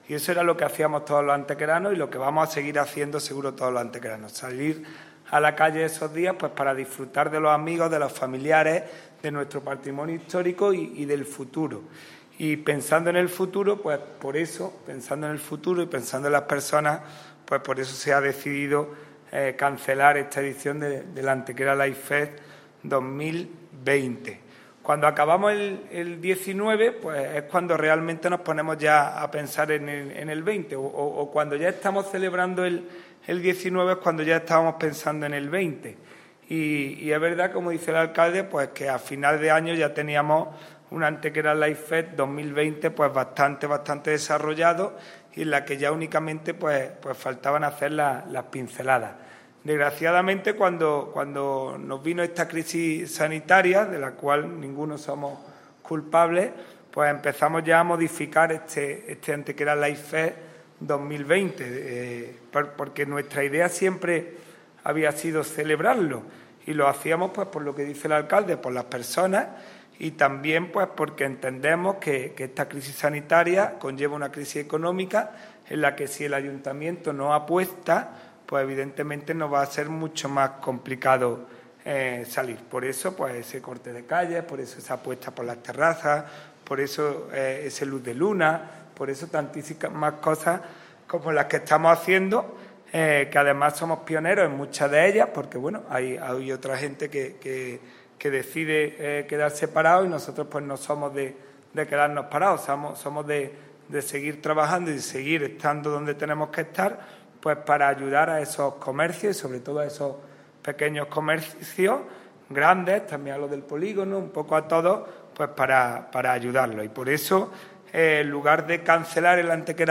El alcalde de Antequera, Manolo Barón, y el teniente de alcalde delegado de Patrimonio Mundial, Juan Rosas, han anunciado en la mañana de hoy viernes en rueda de prensa la cancelación del Antequera Light Fest (ALF), festival de nuevas tecnologías, luz y sonido que cada año a mediados del mes de julio conmemora en nuestra ciudad la declaración del Sitio de los Dólmenes como Patrimonio Mundial de la UNESCO.
Cortes de voz